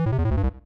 Add sound effects!